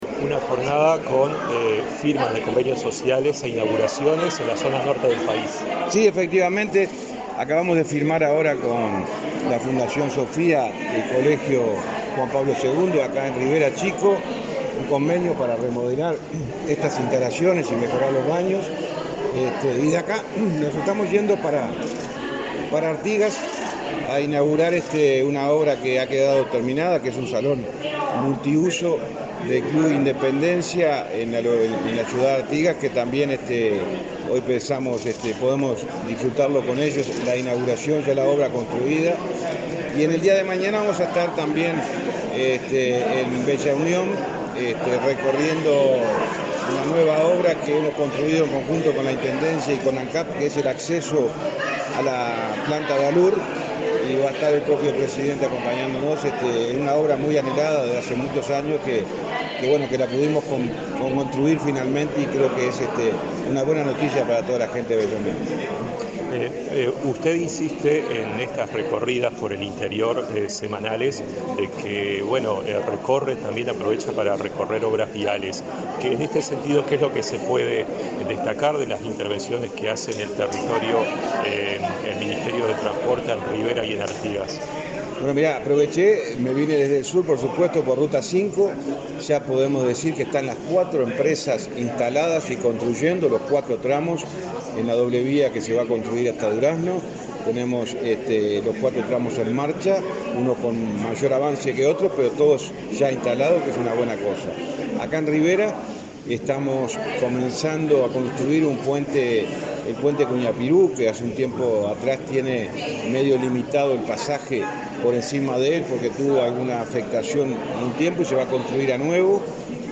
Entrevista al ministro de Transporte y Obras Públicas, José Luis Falero